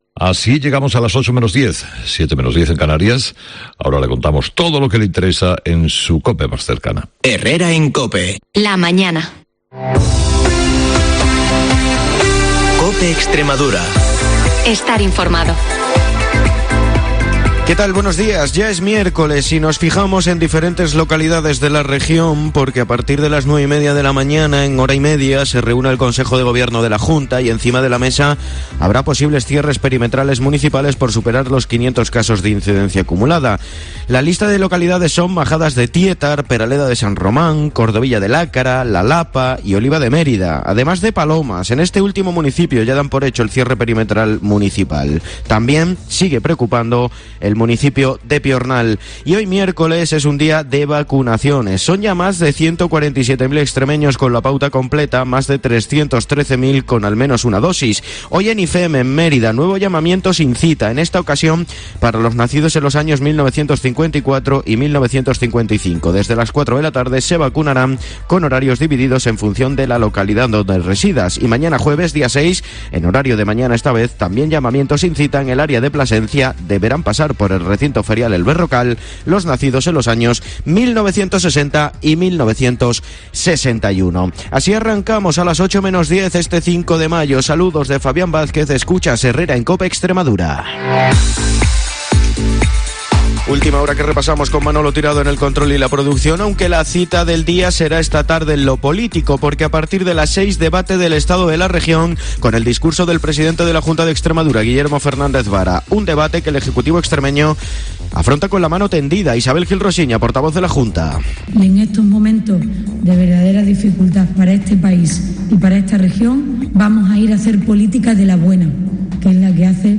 el informativo más escuchado de la radio en la región.